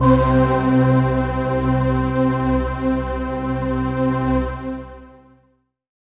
36ab01pad-c.aif